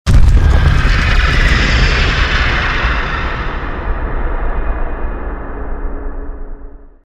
Root > sounds > weapons > hero > underlord